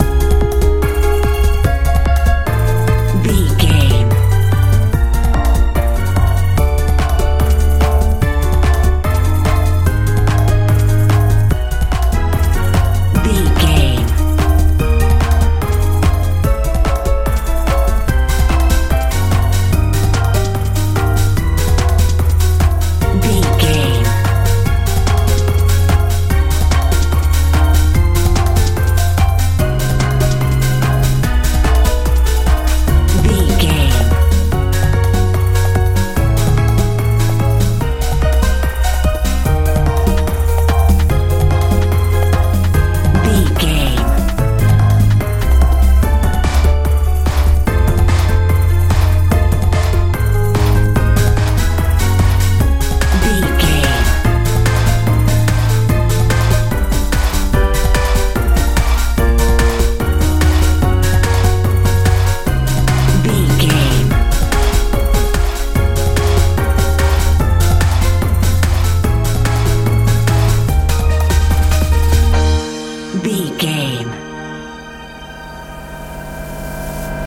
jpop
Ionian/Major
fun
playful
bass guitar
drums
synthesiser
80s
90s